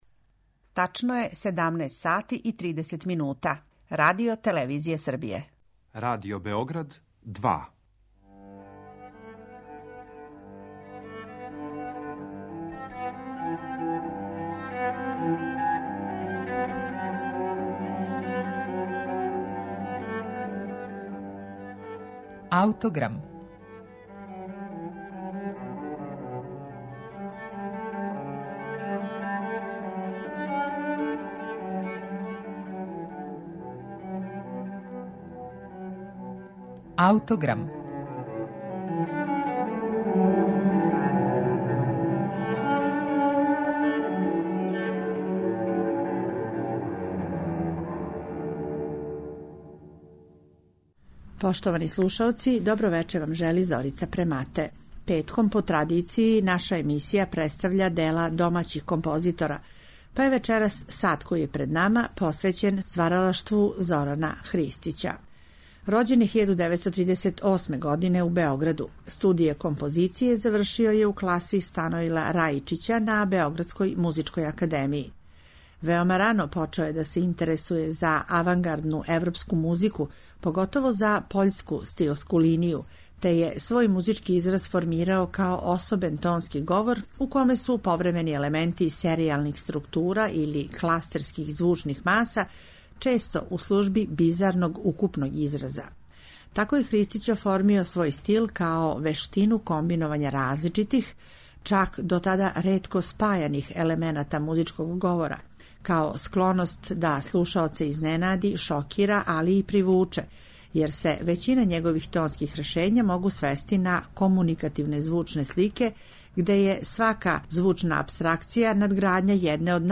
Емитоваћемо концертни снимак са премијере